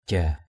/ʥa:/ (d.) tên, danh (trai) = le dénommé. the so-called (male).